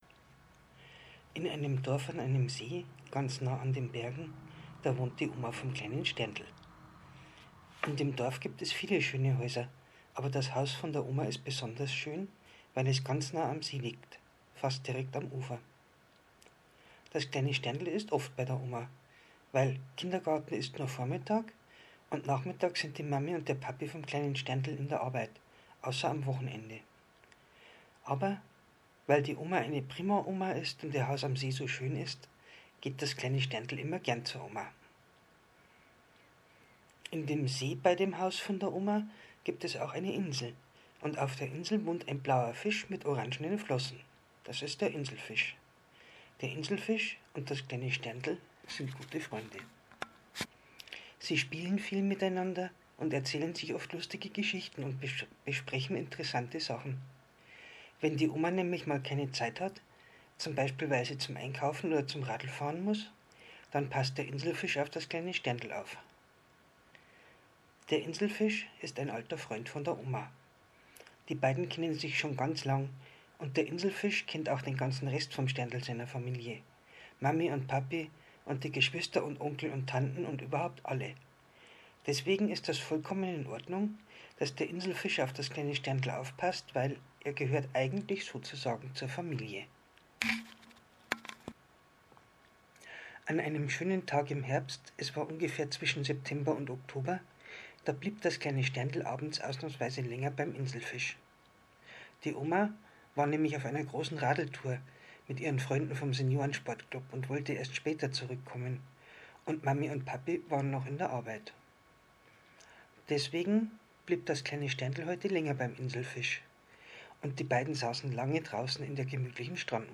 Inselfisch und Sterndl als Hörbuch